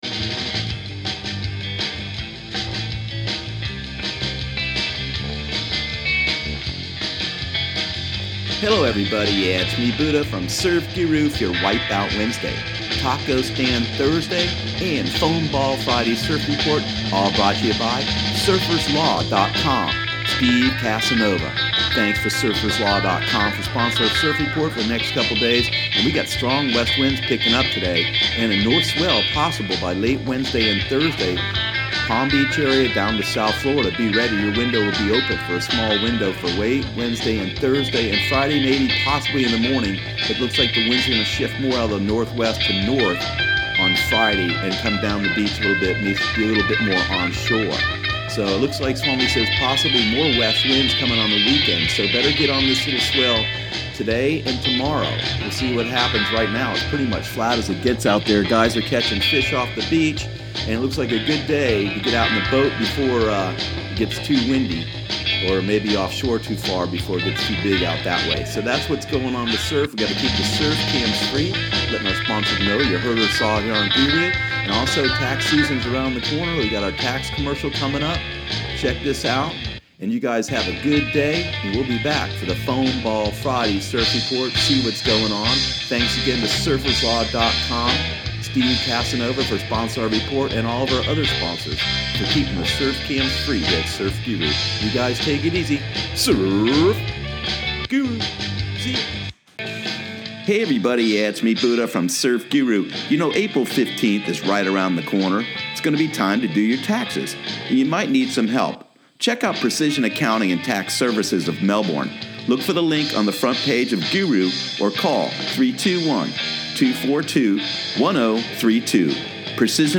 Surf Guru Surf Report and Forecast 03/21/2018 Audio surf report and surf forecast on March 21 for Central Florida and the Southeast.